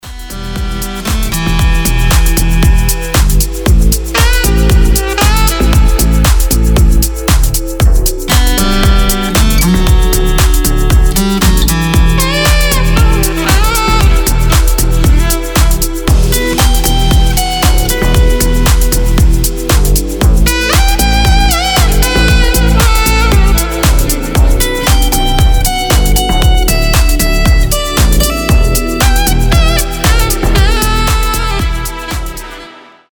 гитара
retromix
без слов
красивая мелодия
chillout
Саксофон
Lounge
Узнаваемая мелодия в красивом звучании